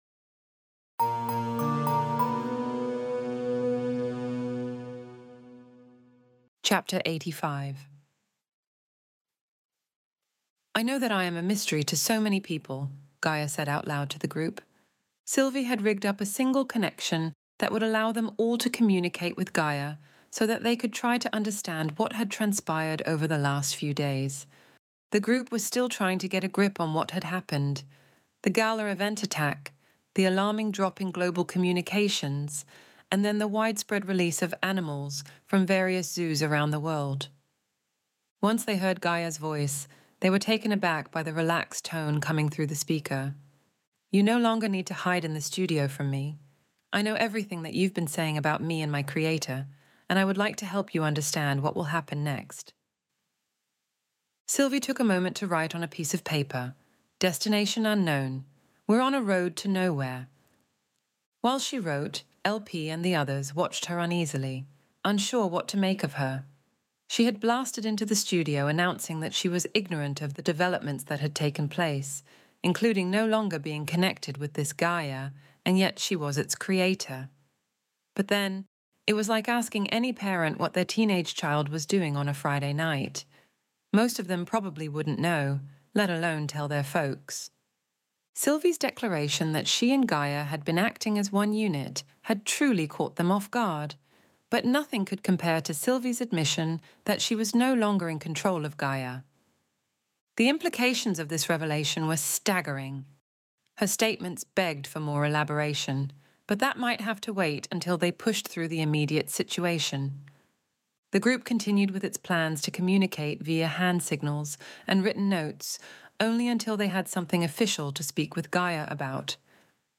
Extinction Event Audiobook Chapter 85